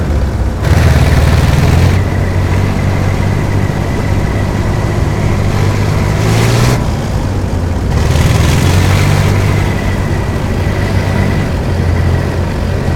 tank-engine-1.ogg